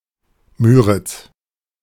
Müritz (výslovnost: IPA: [ˈmyːʁɪt͡s], zvuk